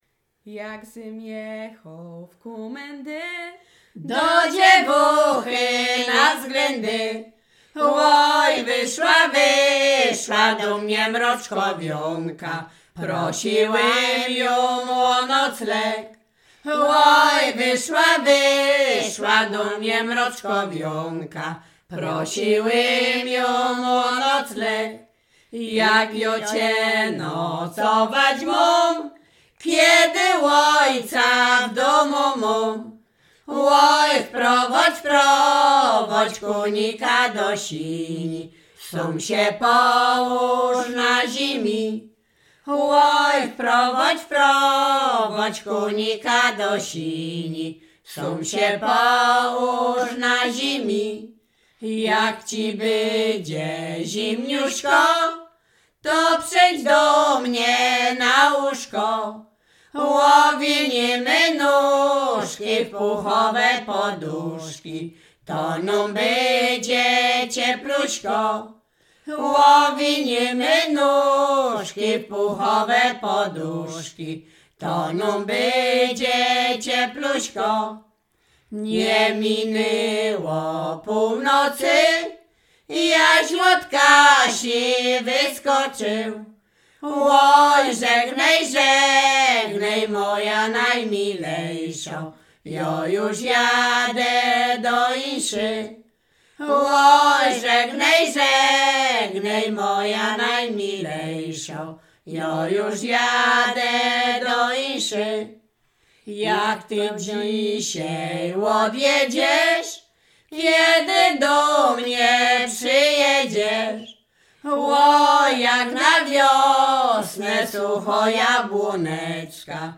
Śpiewaczki z Mroczek Małych
województwo łodzkie, powiat sieradzki, gmina Błaszki, wieś Mroczki Małe
liryczne miłosne